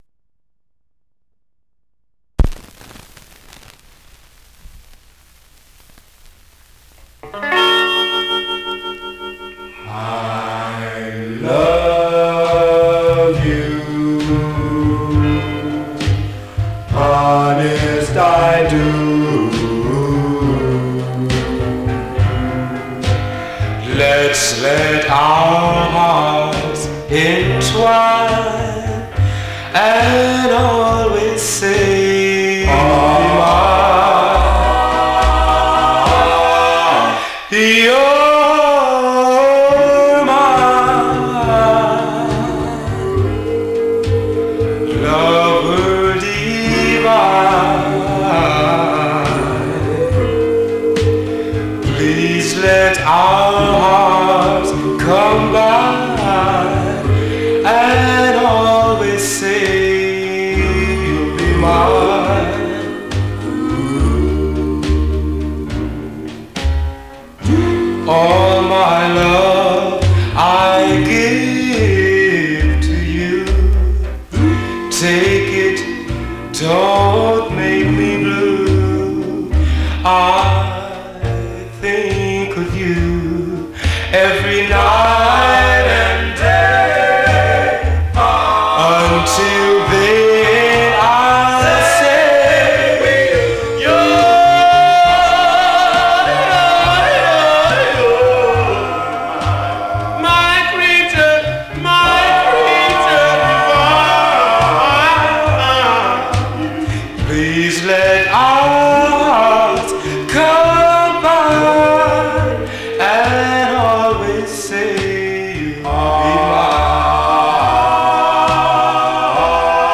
Mono
Male Black Group Condition